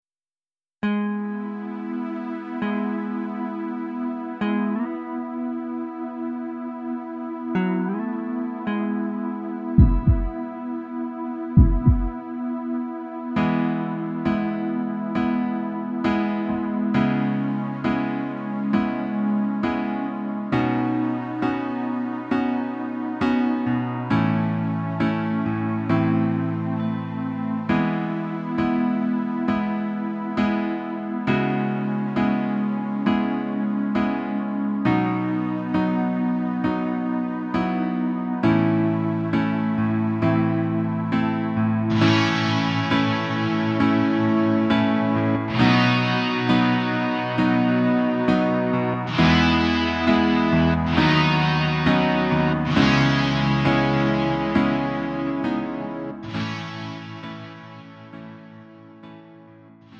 음정 원키
장르 가요 구분 Lite MR
Lite MR은 저렴한 가격에 간단한 연습이나 취미용으로 활용할 수 있는 가벼운 반주입니다.